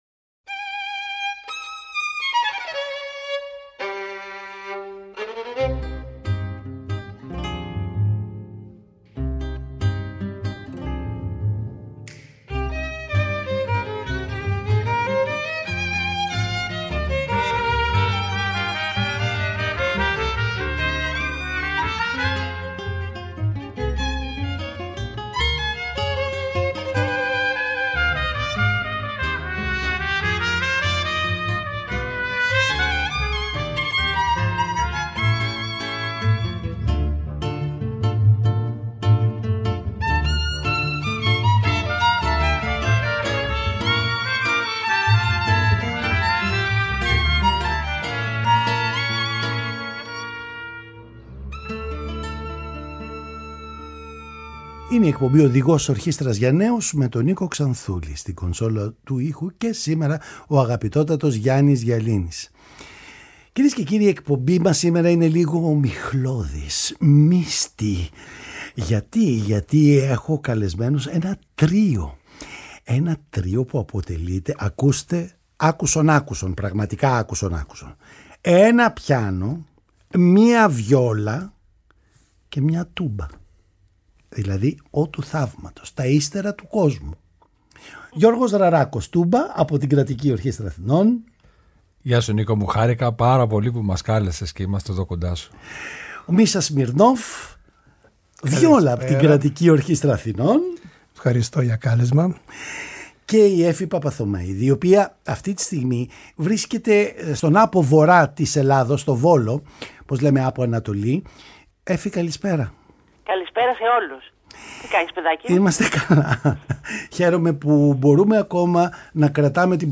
Βιόλα, Τούμπα, Πιάνο